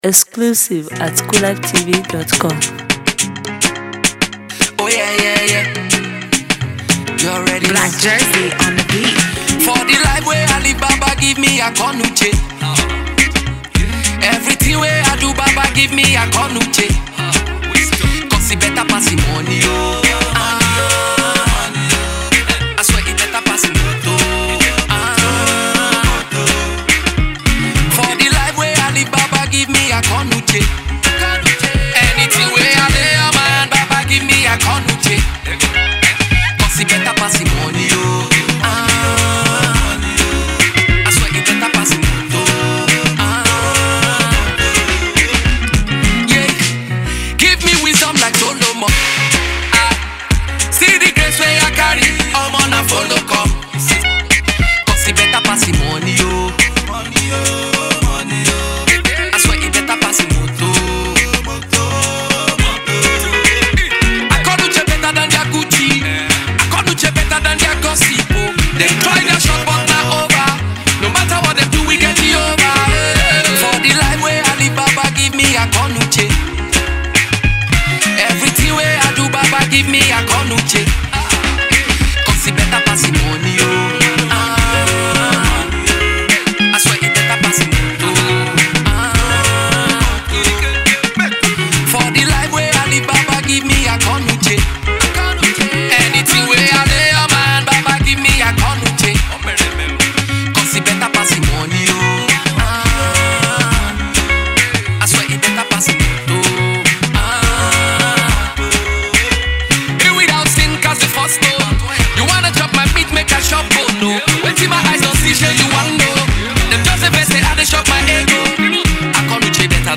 Afro Music 2018 Download